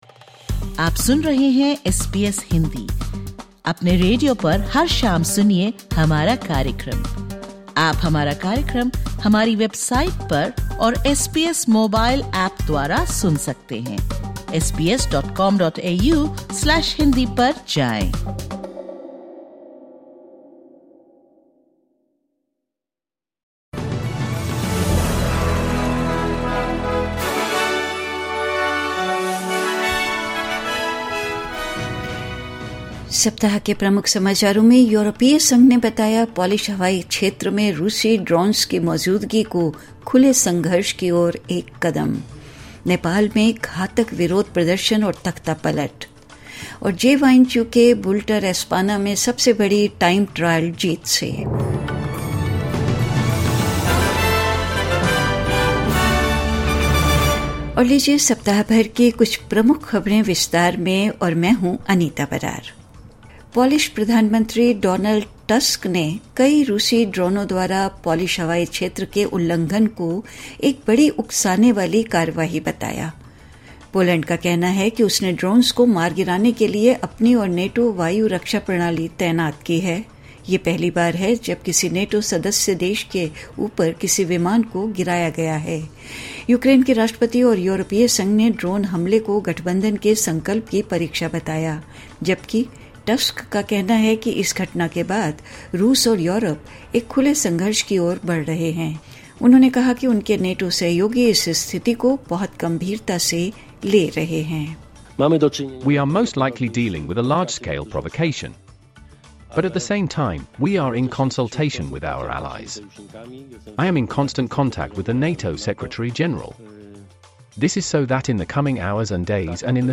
साप्ताहिक समाचार 13 सितम्बर 2025